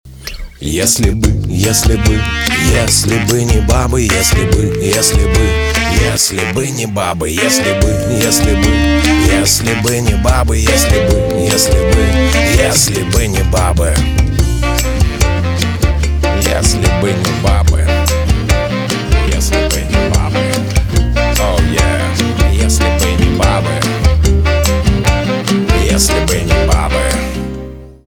русский рок , труба , барабаны , спокойные